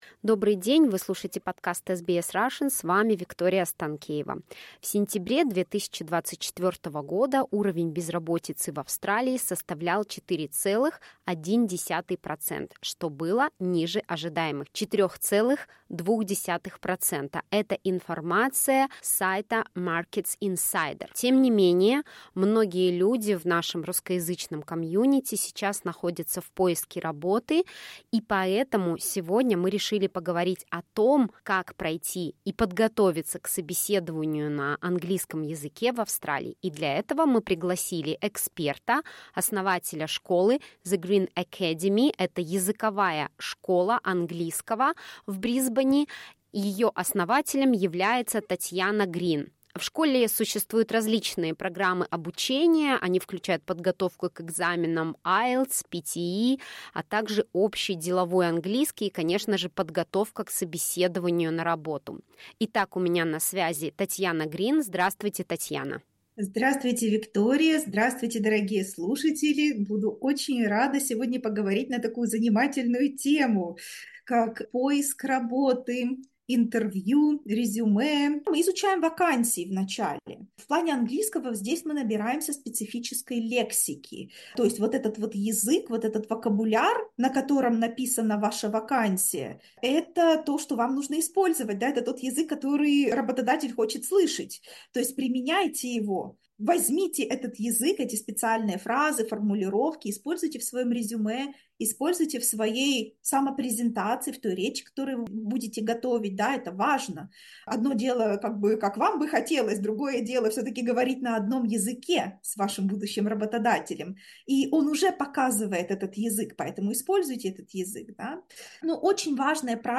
Как подготовиться к собеседованию: Интервью с руководителем школы английского языка